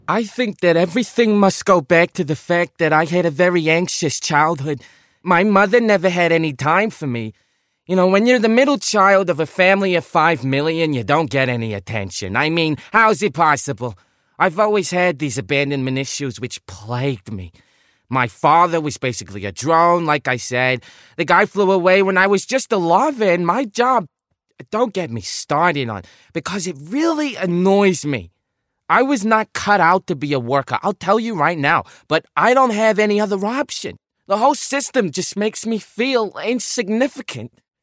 Ant’ny New York